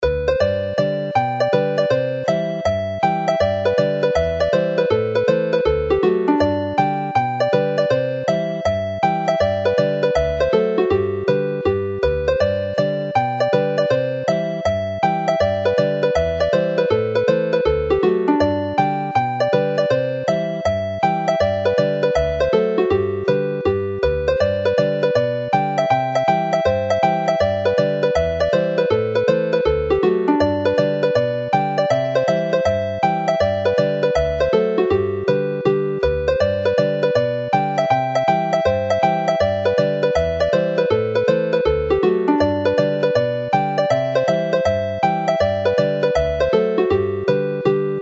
The sound files for the two tunes run as hornpipes and the score illustrations shown below the scores of the tunes give a fairly accurate portrayal of this.
This is how it is played as a hornpipe.
Note how connecting notes across boundaries gives the lilt to the tune.